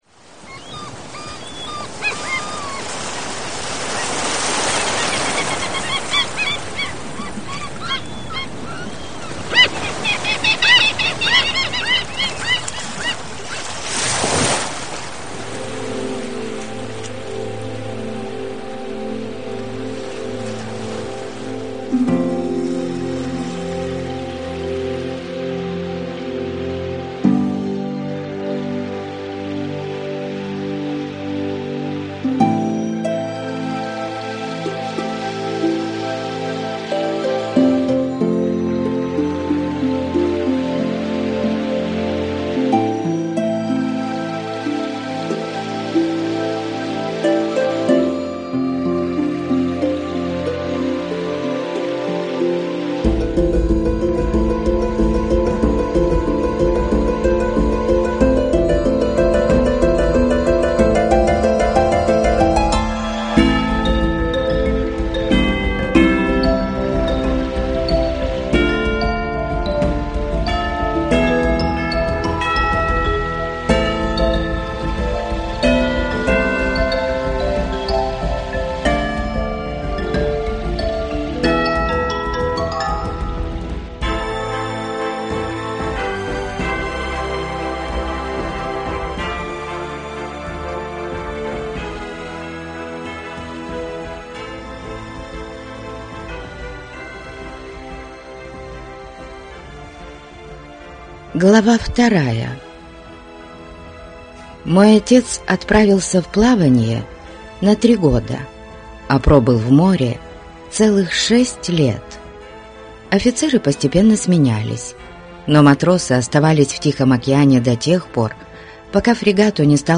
Аудиокнига Ромен Кальбри | Библиотека аудиокниг